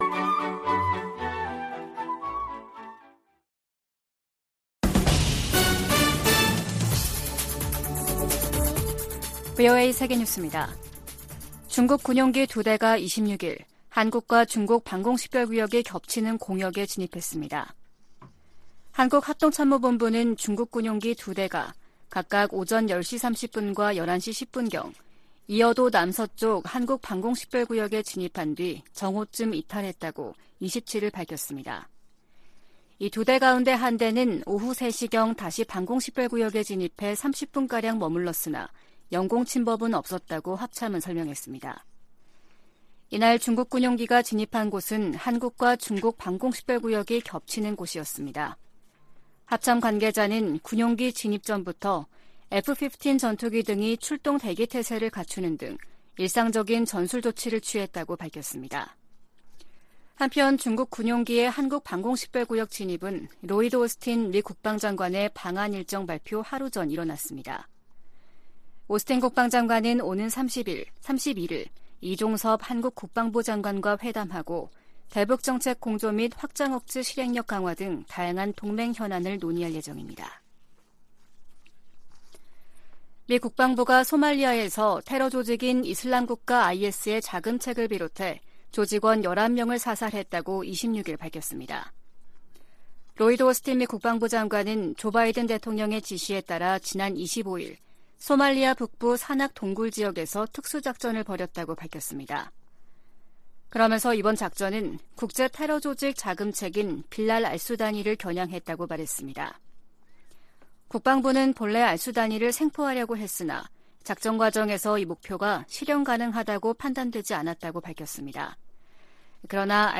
VOA 한국어 아침 뉴스 프로그램 '워싱턴 뉴스 광장' 2023년 1월 28일 방송입니다. 미국과 한국의 국방장관들이 31일 서울에서 회담을 갖고 대북정책 공조, 미국 확장억제 실행력 강화 등 다양한 동맹 현안들을 논의합니다. 미국 정부가 러시아 군사조직 바그너 그룹을 국제 범죄조직으로 지목하고 현행 제재를 강화했습니다.